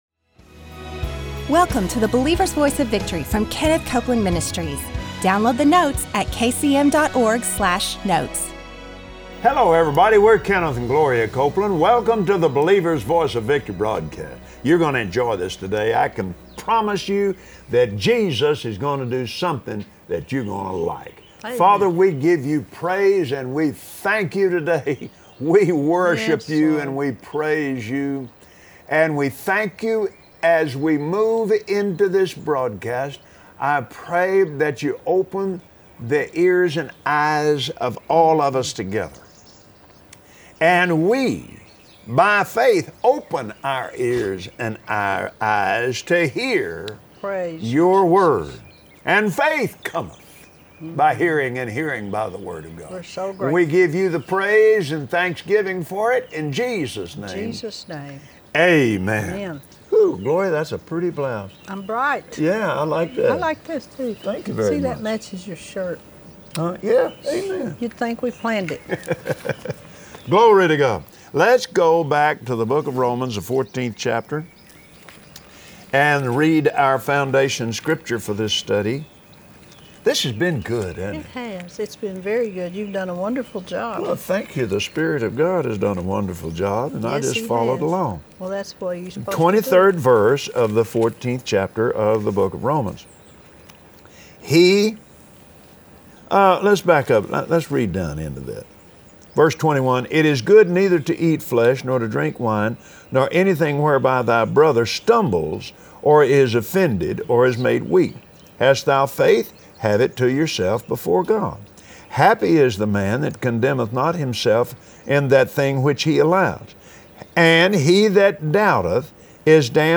Join Kenneth and Gloria Copeland today and experience the freedom that comes from casting all your cares on Him. Now, here are Kenneth and Gloria.